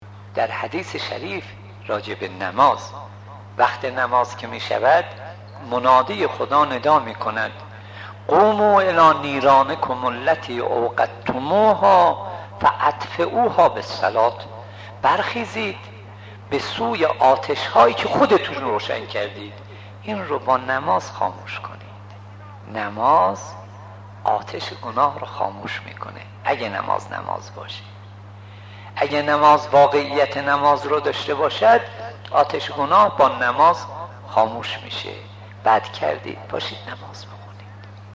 سخنان حاجاقا صدیقی